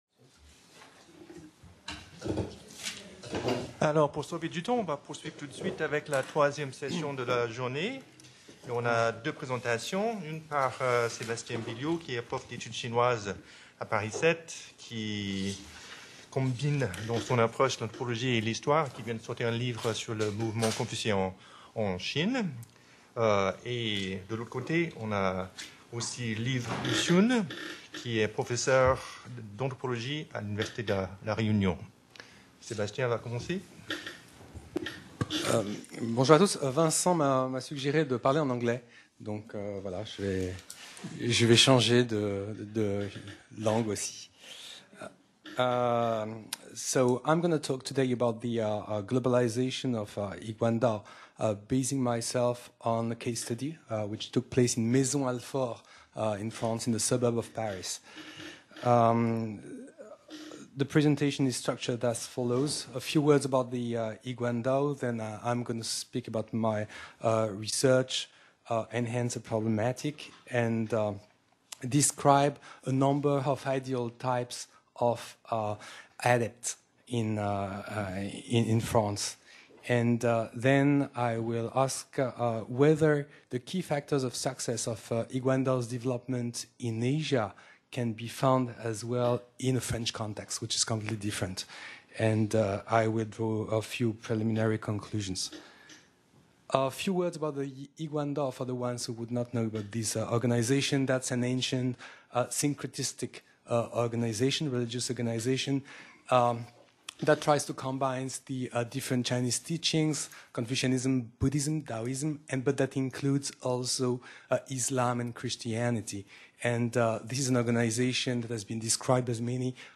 Colloque international du 18 au 20 mai 2016 au CNRS site Pouchet, Paris 17e et à l'INALCO, Paris 13e.